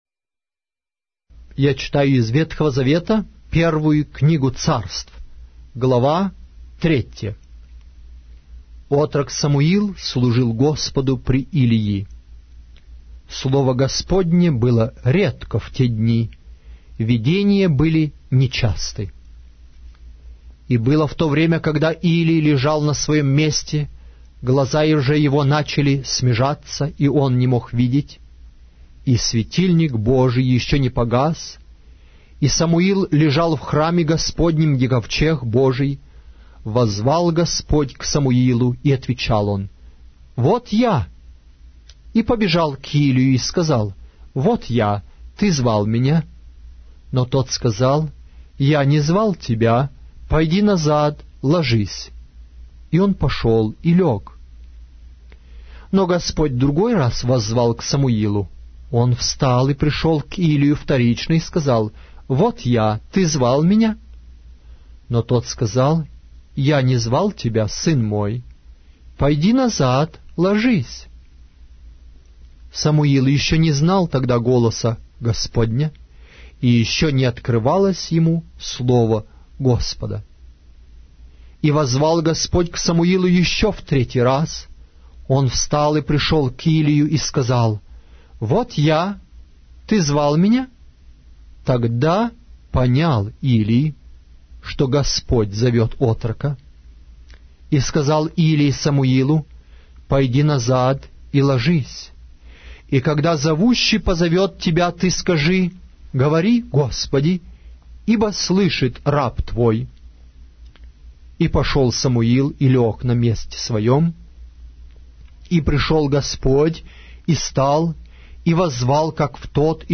Аудиокнига: 1-я Книга Царств